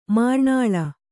♪ mārṇāḷa